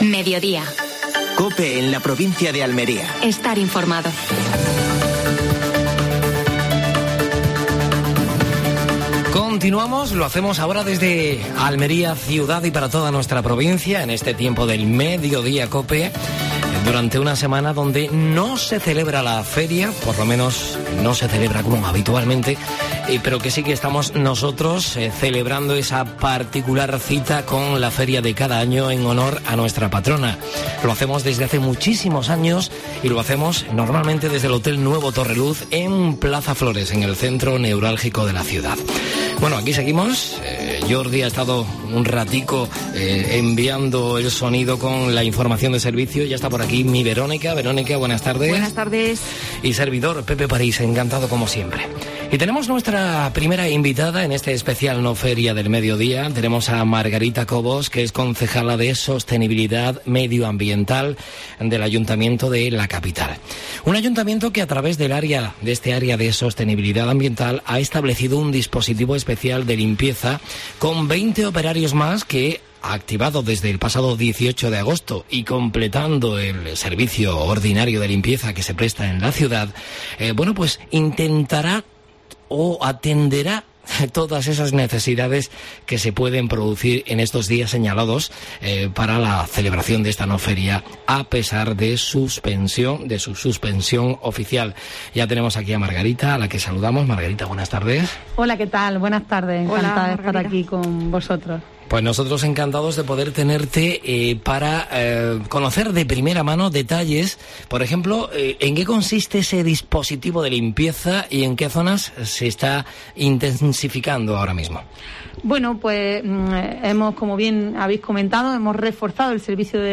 Entrevistas a Margarita Cobos (concejala del Ayuntamiento de Almería)